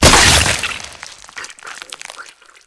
2D-Platformer/Assets/Sounds/Impacts/Bug/crack12.mp3-3.wav at master
crack12.mp3-3.wav